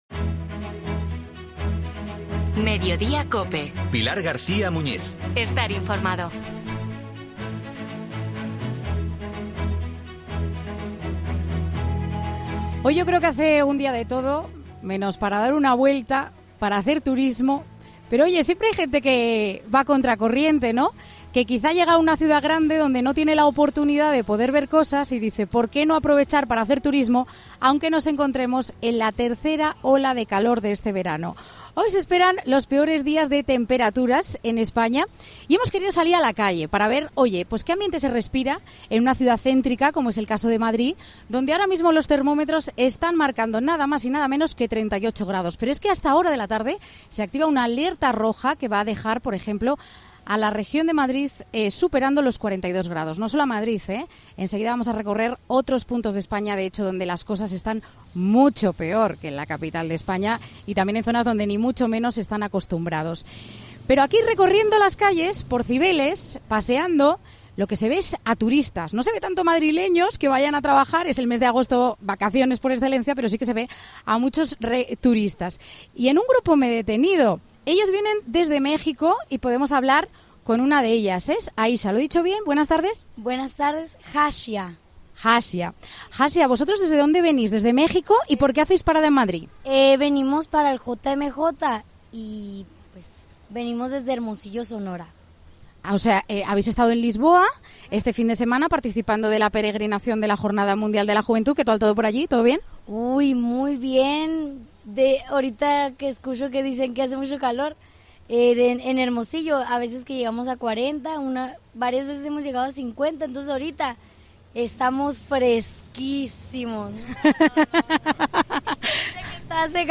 Mediodía COPE sale a la calle para comprobar cómo se vive el peor día de la tercera ola de calor